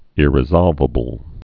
(ĭrĭ-zŏlvə-bəl)